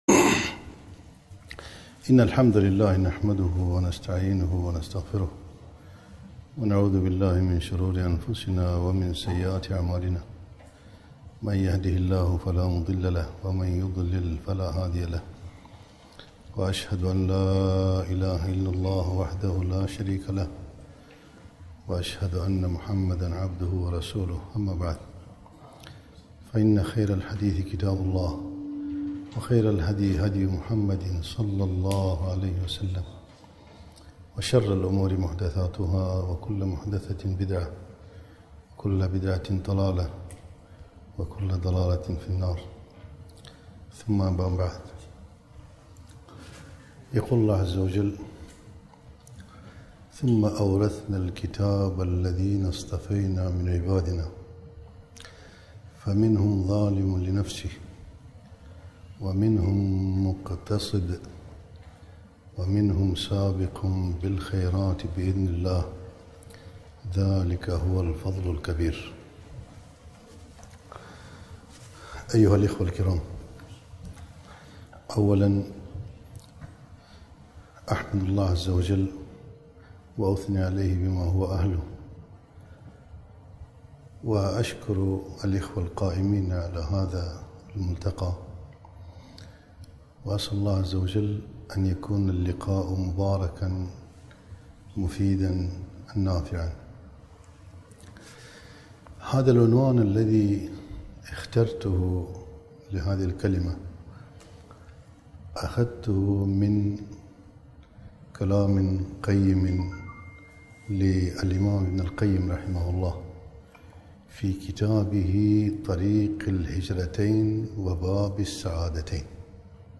محاضرة - أقسام السائرين إلى الله